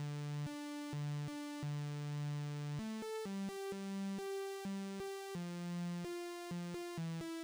BAL Backing Synth Riff D-A.wav